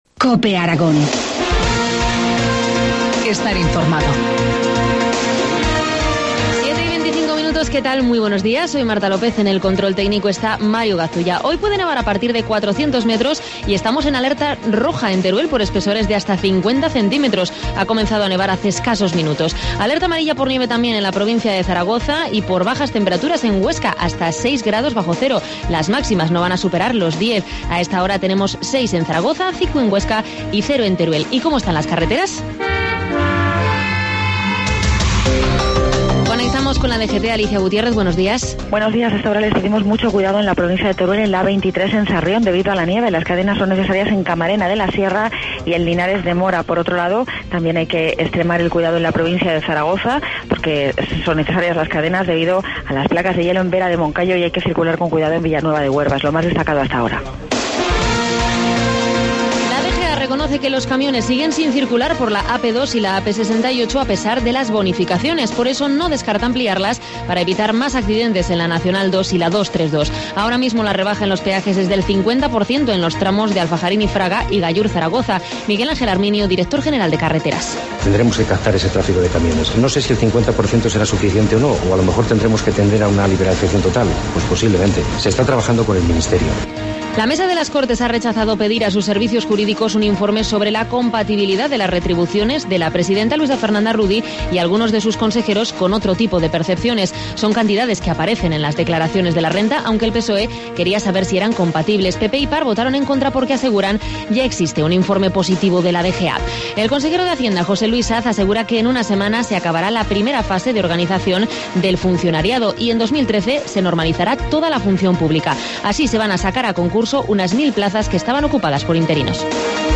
Informativo matinal, jueves 28 de febrero, 7.25 horas